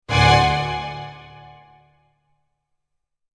Dramatic Orchestral Stab Sound Effect
Description: Dramatic orchestral stab sound effect. A powerful orchestral hit, perfect for enhancing tension in a scene. Tense cinematic stinger.
Dramatic-orchestral-stab-sound-effect.mp3